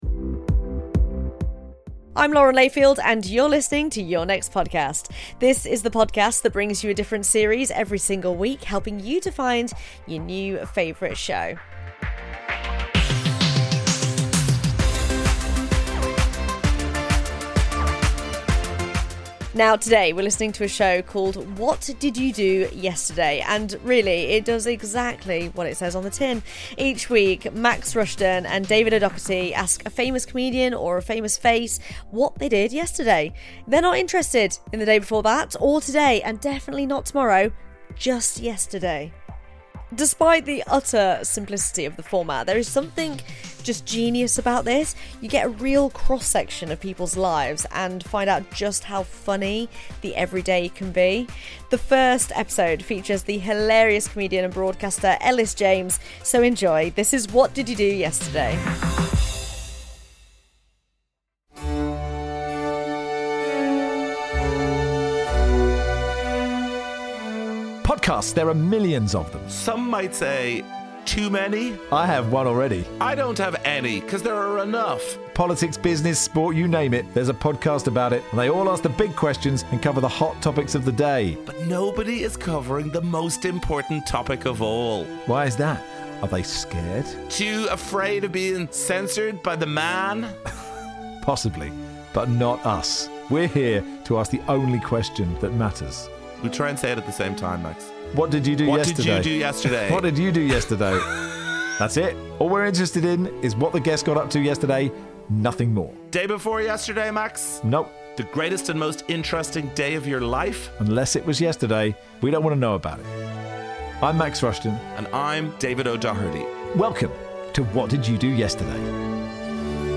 Lauren Layfield introduces What Did You Do Yesterday? on the series recommendation show Your Next Podcast.
Max Rushden and David O'Doherty ask a celebrity guest what they did yesterday.
Their first guest is the comedian and broadcaster Elis James.